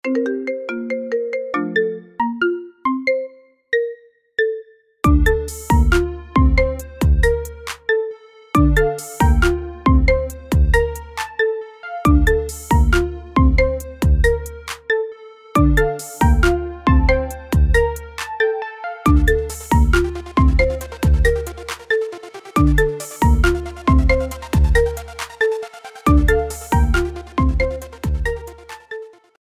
Sonnerie Gratuite